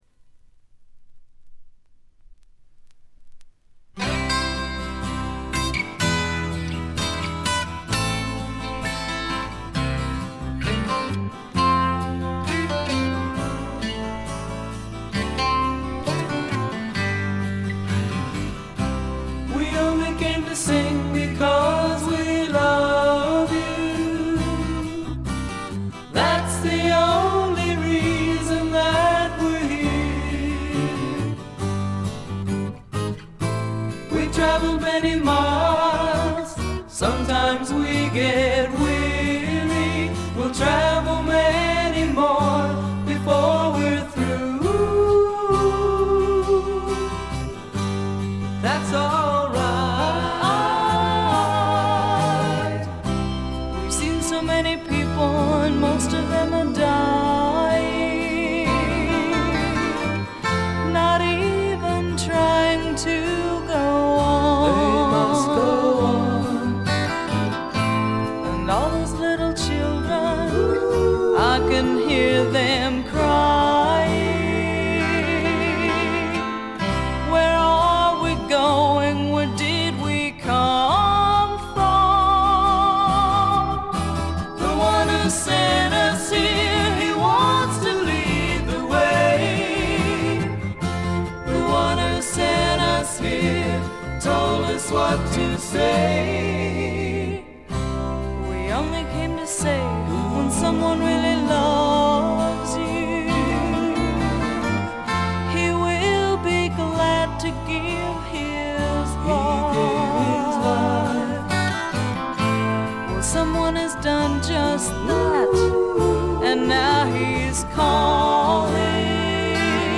部分試聴ですがほとんどノイズ感無し。
クリスチャン・ミュージックの男女混成グループ
試聴曲は現品からの取り込み音源です。